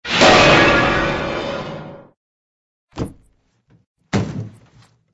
AA_drop_piano.ogg